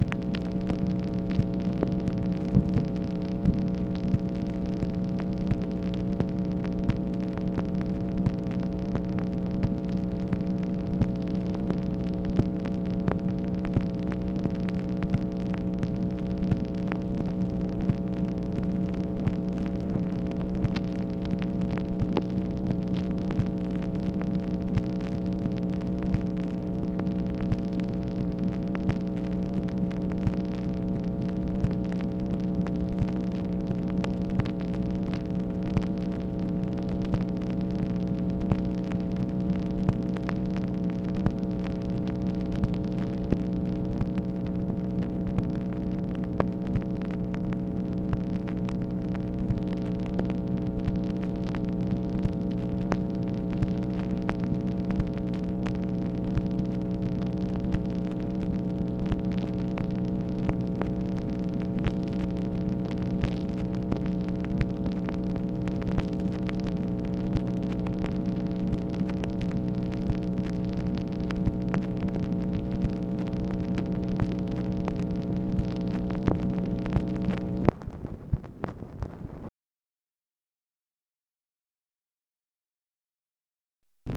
MACHINE NOISE, October 23, 1964
Secret White House Tapes